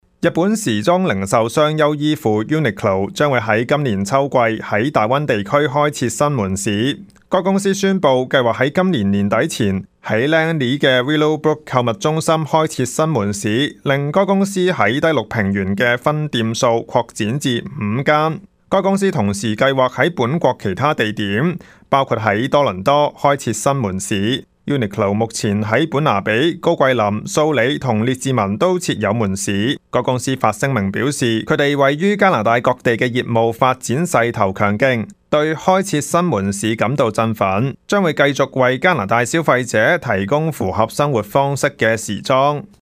news_clip_19980.mp3